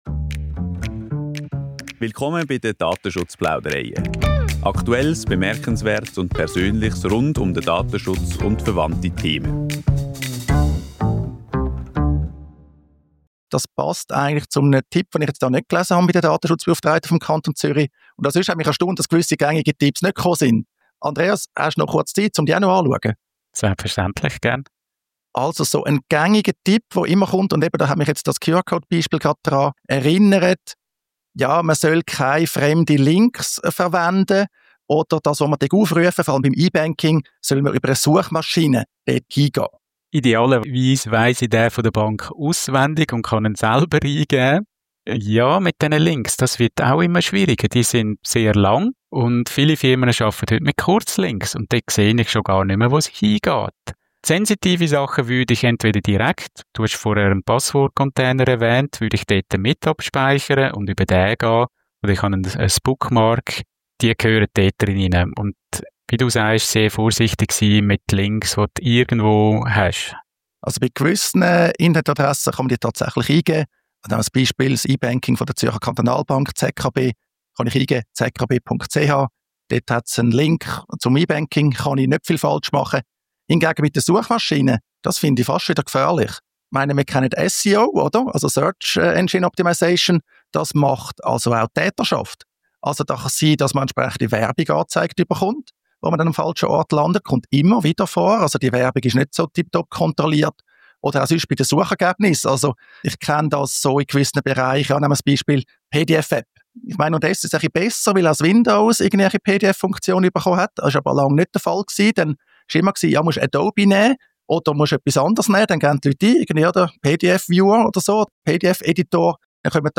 Diskussion über weitere Tipps für den Schutz vor Phishing